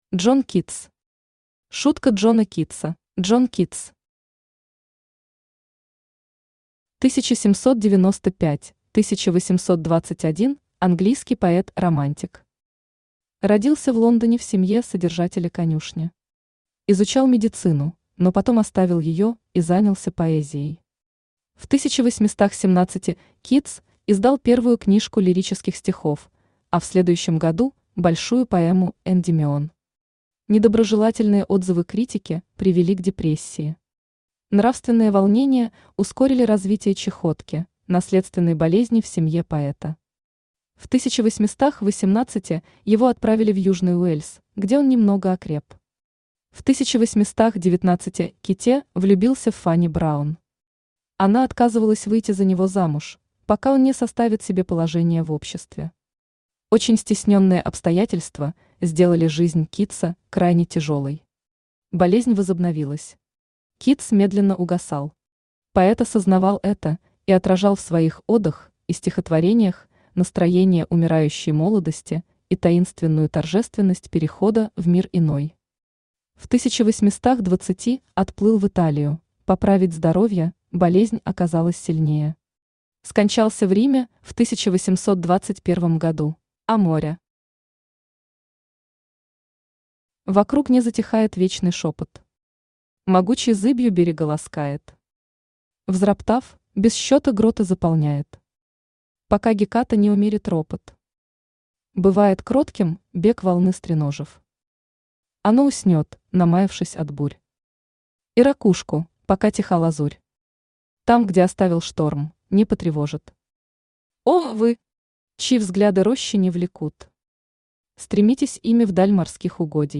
Аудиокнига Шутка Джона Китса | Библиотека аудиокниг
Aудиокнига Шутка Джона Китса Автор Джон Китс Читает аудиокнигу Авточтец ЛитРес.